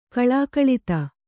♪ kaḷākaḷita